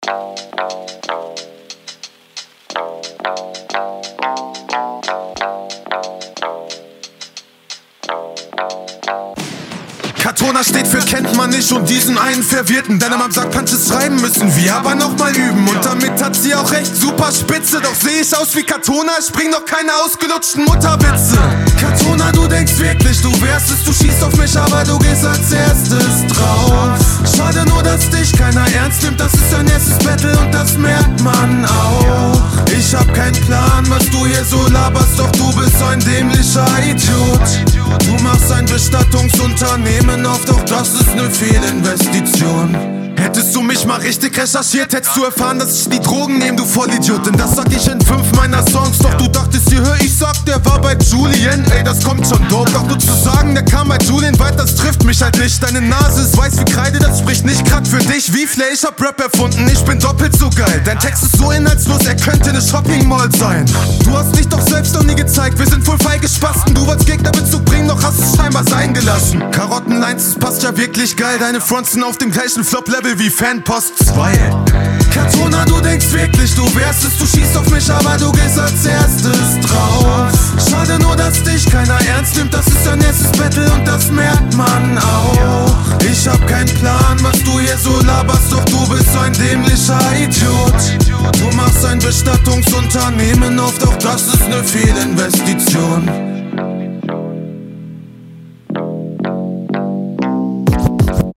Also vorweg muss ich deine Mische loben, die klingt sehr ausgereift.
Stimme ist richtig nice.